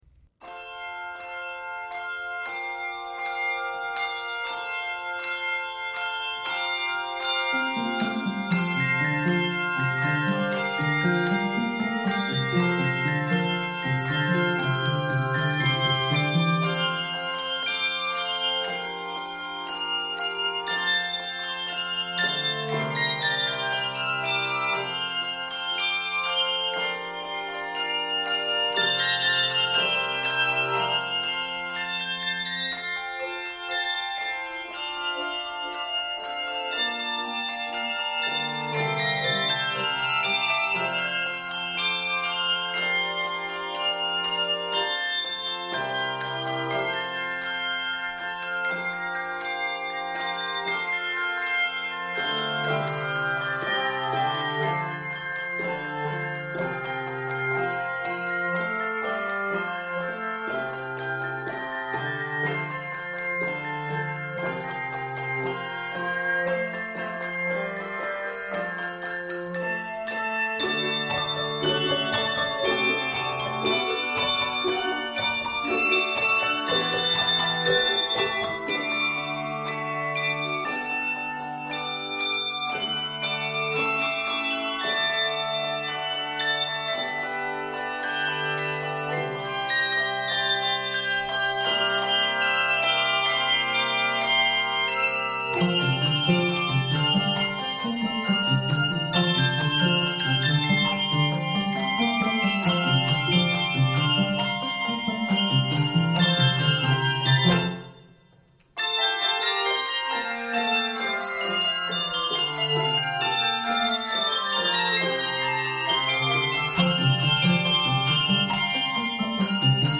Octaves: 4-6